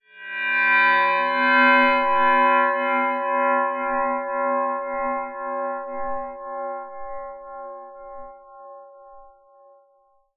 metallic_glimmer_drone_02.wav